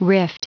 Prononciation du mot rift en anglais (fichier audio)
Prononciation du mot : rift